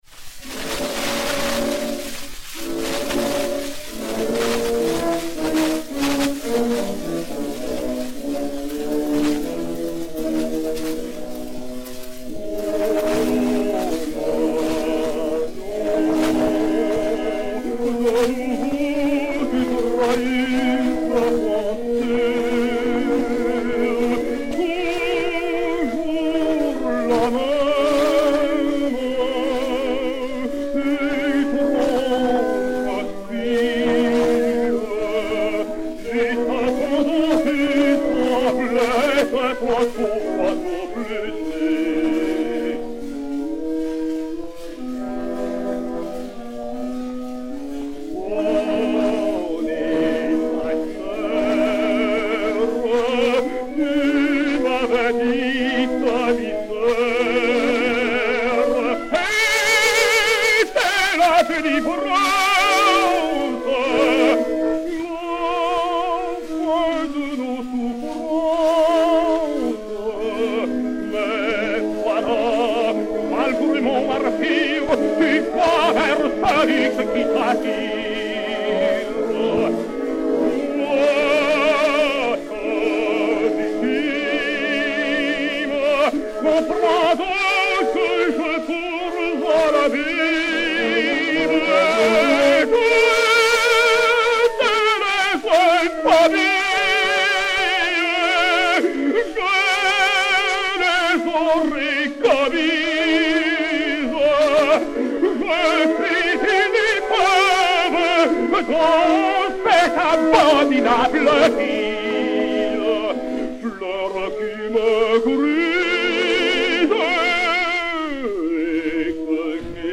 Léon Campagnola (Des Grieux) et Orchestre
Disque Pour Gramophone 4-32296, mat. 16996u, enr. à Paris le 05 décembre 1911